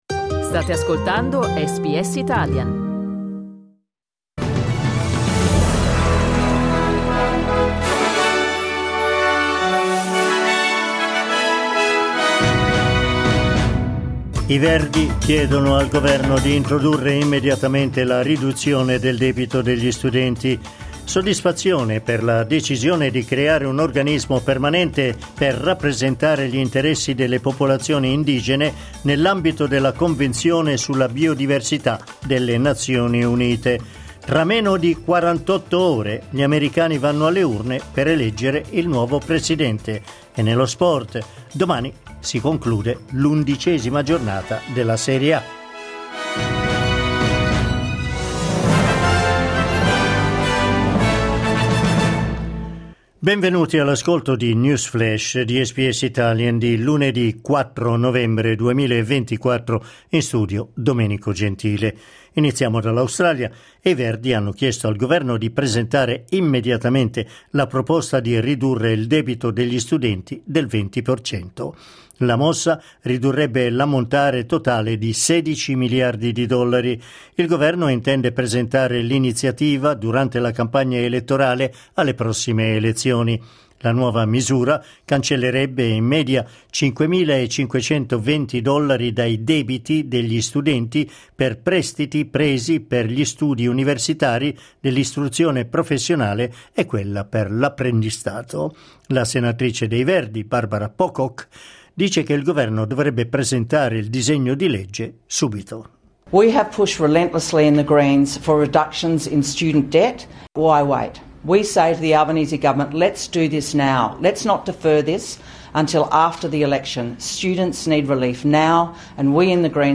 L’aggiornamento delle notizie di SBS Italian.